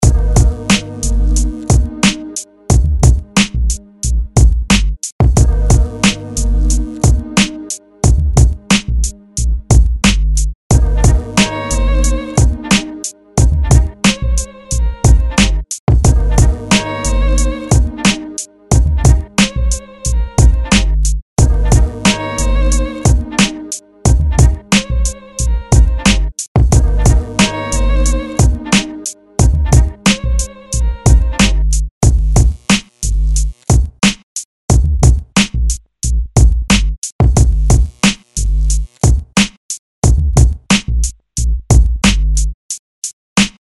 • Жанр: Рэп
Пробник минус...